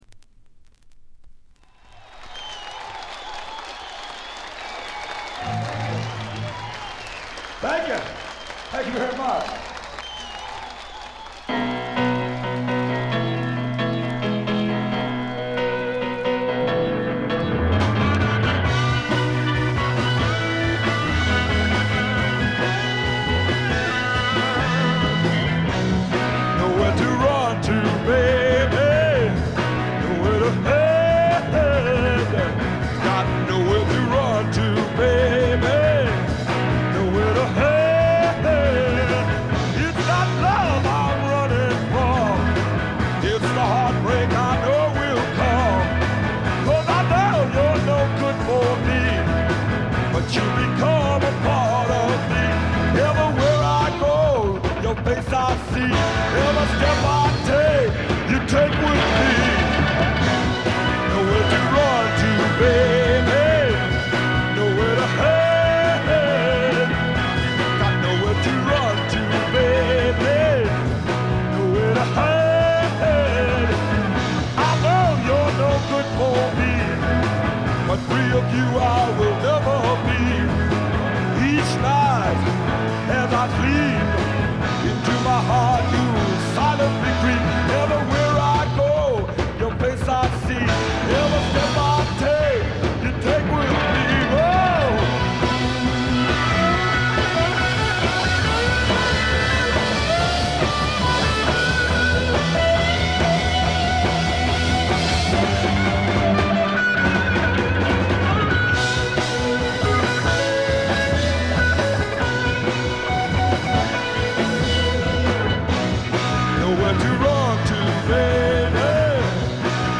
静音部（ほとんどないけど）で軽いチリプチ。
本作は地元デトロイトでのライヴ盤で臨場感にあふれる素晴らしい演奏が聴けますよ。
ヴォーカルもギターも最高です。
試聴曲は現品からの取り込み音源です。
Recorded live at the Eastown Theatre, Detroit, Michigan.